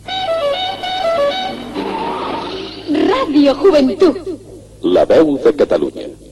Indicatiu de l'emissora: La Veu de Catalunya